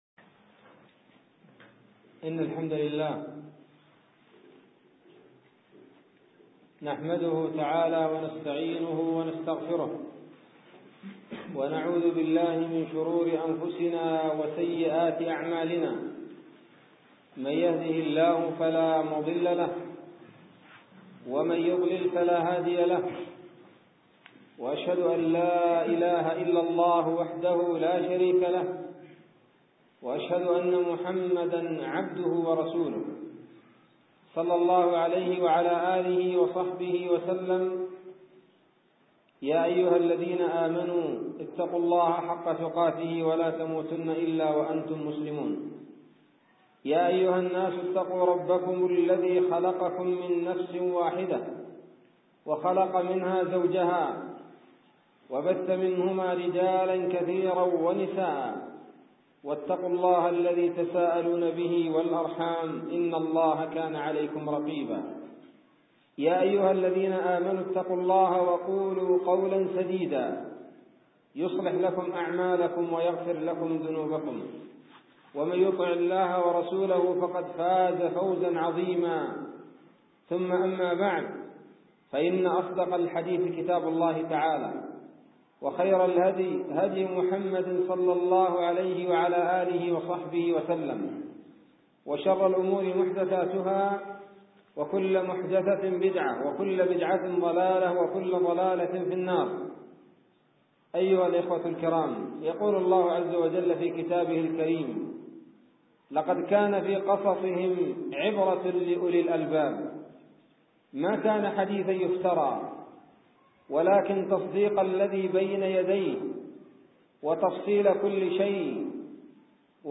محاضرة بعنوان :((قصة سلمان الفارسي وفيها بيان عن البحث عن الحقيقة